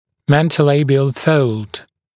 [ˌmentəu’leɪbɪəl fəuld][ˌмэнтоу’лэйбиэл фоулд]подбородочная складка